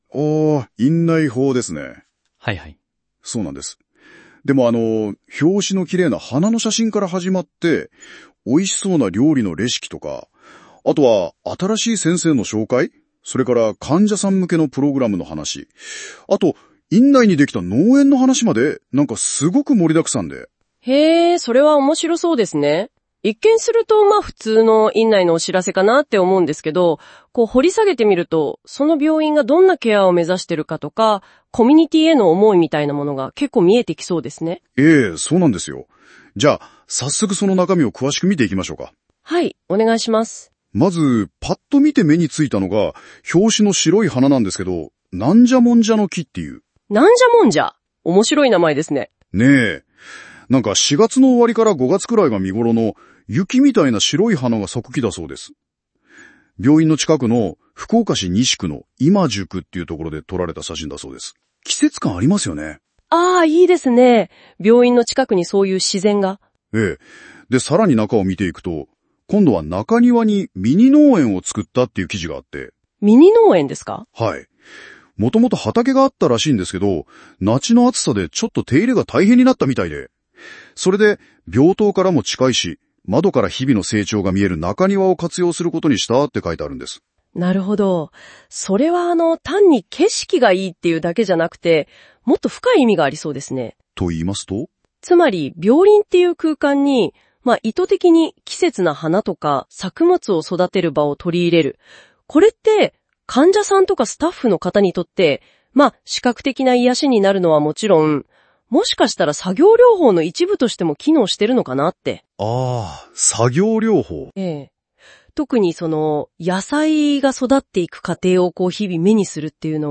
かいせい通信の内容を、AIに解説してもらった音声を公開します。
回生AIラジオ という架空のラジオ番組で、パーソナリティーは男性がDJカイン、女性がセイコです。
時折たどたどしい表現はあるものの、かいせい通信を読み込ませただけで台本なし、AIが自分で考えて？しゃべっています。